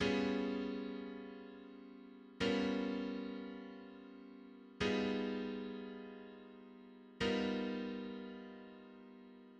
Complex dominant chords
Dominant 9/5, Dominant 9/5, Dominant 9/5, Dominant 9/5 chords on G i.e. G7(95), G7(95), G7(95), G7(95). All of these chords can be written as G7alt.